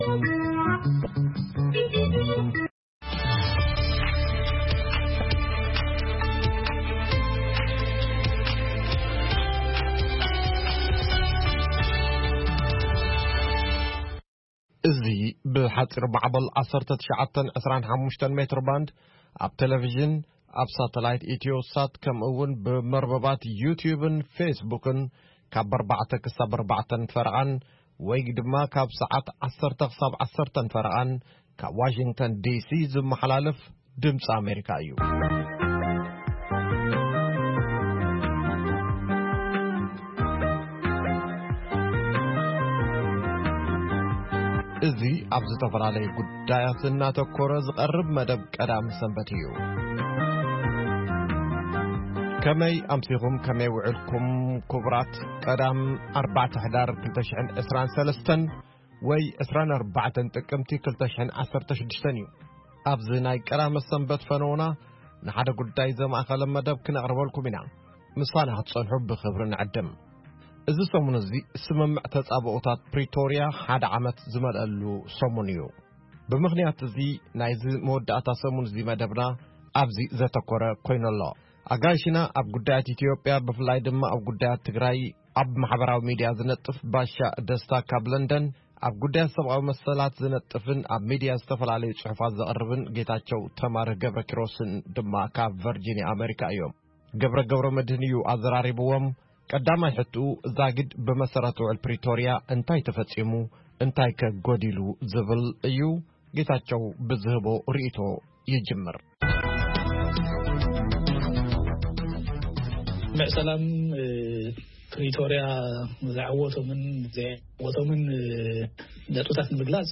ድምጺ ኣሜሪካ ፈነወ ቛንቛ ትግርኛ ካብ ሶኑይ ክሳብ ሰንበት ይፍነው። ፈነወ ቛንቛ ትግርኛ ካብ ሶኑይ ክሳብ ዓርቢ ብዕለታዊ ዜና ይጅምር፥ እዋናዊ ጉዳያትን ሰሙናዊ መደባት'ውን የጠቓልል ።ቀዳምን ሰንበትን ኣብቲ ሰሙን ዝተፈነው መደባት ብምድጋም ፈነወ ቛንቛ ትግርኛ ይኻየድ።